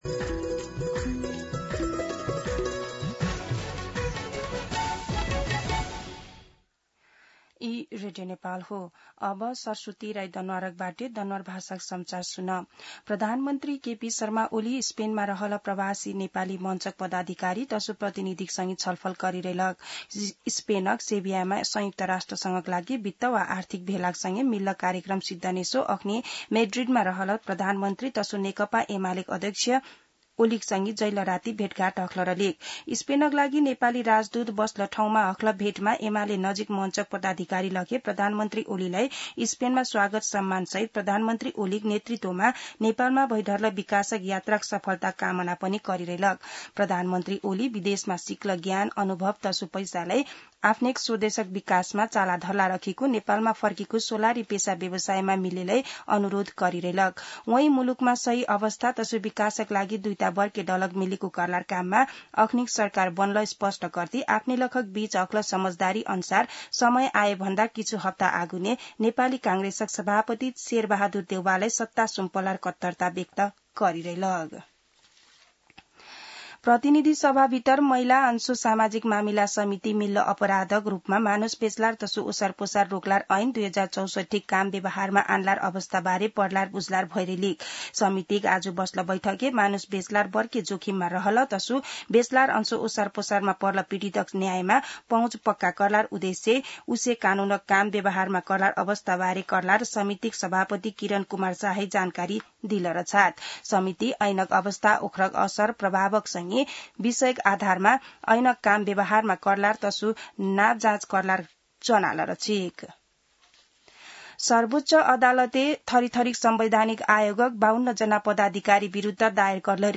दनुवार भाषामा समाचार : १९ असार , २०८२
Danuwar-News-3-19.mp3